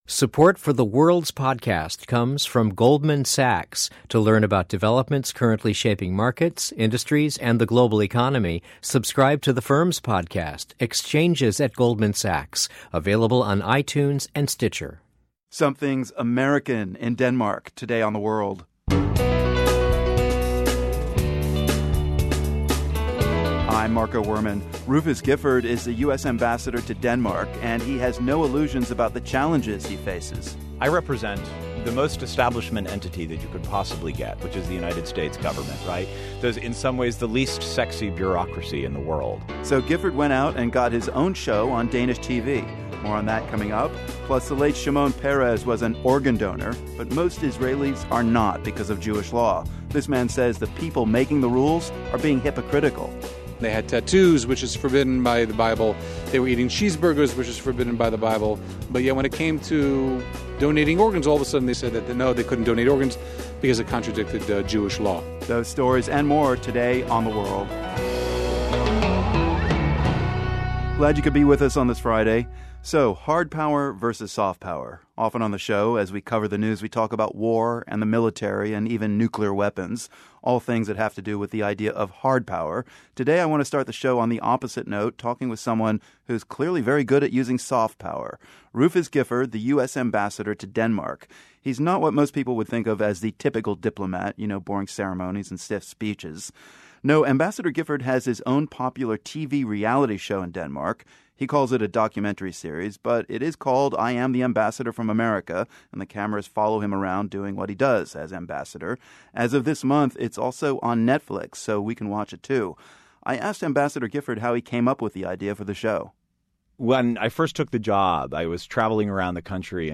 The US ambassador to Denmark has his own reality show. Ambassador Gifford stops by to tell Marco Werman all about his use of "soft power." We also get the story of an Ethiopian immigrant in California who decided to bring a little taste of his homeland to the Bay Area — and now wants to grow California crops in Ethiopia.